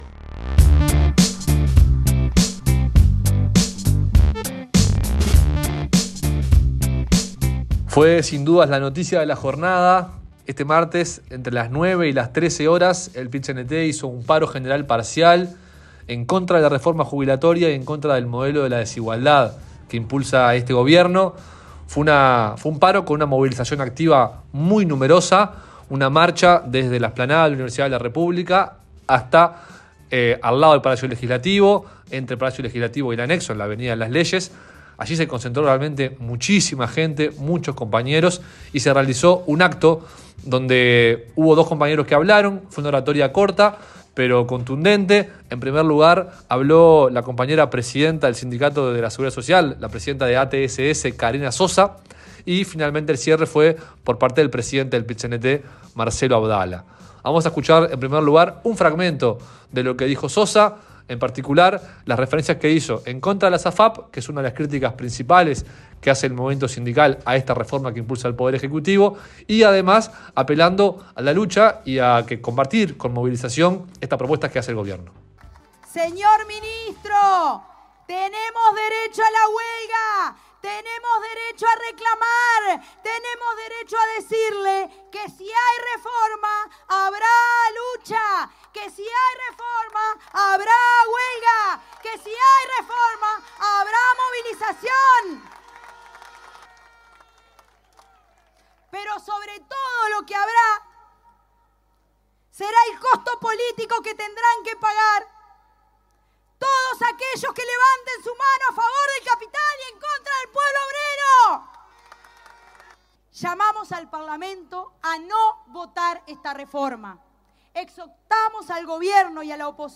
Este martes el PIT-CNT realizó un paro general parcial de 9 a 13 horas, con una movilización que consistió en una marcha desde la Universidad de la República hasta el Palacio Legislativo.
Allí tuvo lugar la parte oratoria